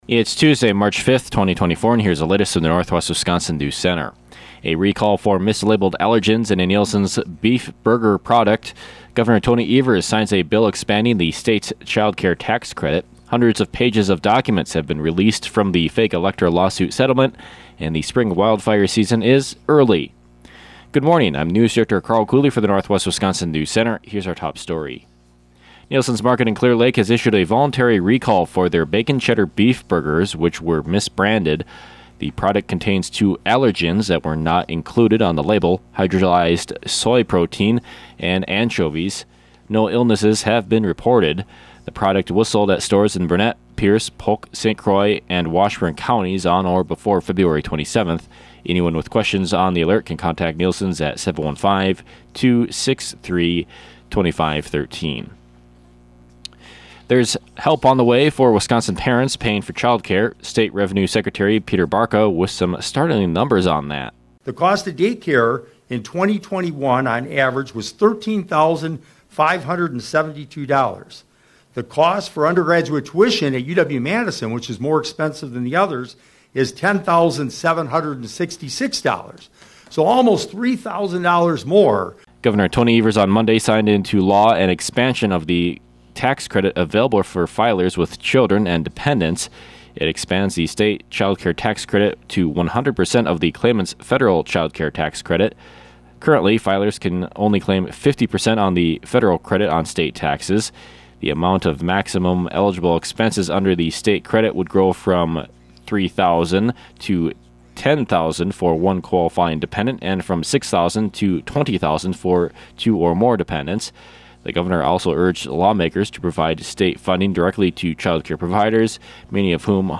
These stories and more on today’s local newscast.